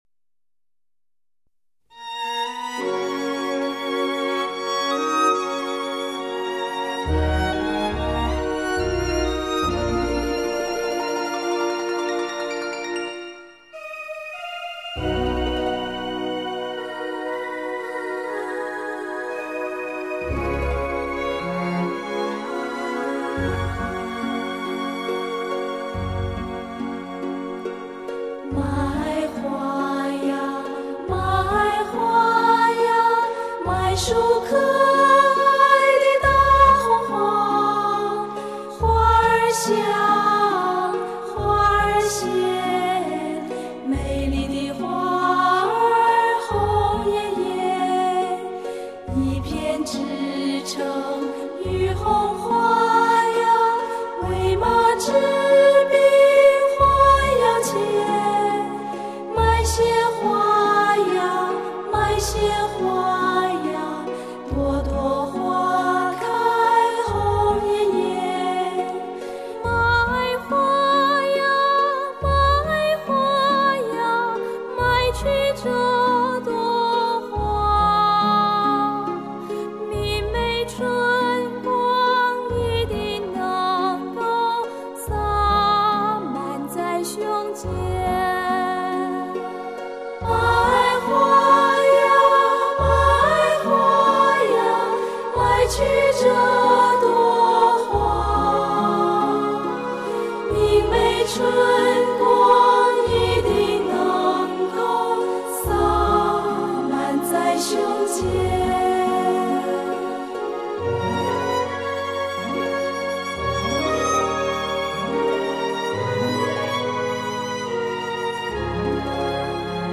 这些曾与大家的生命共荣共生的老歌，经过重新配器改编
录音并非是马虎交差之作。
合唱队员各声部线条之清晰，音色调配之和谐，在Hi-Fi音响中聆听，令人赏心悦耳！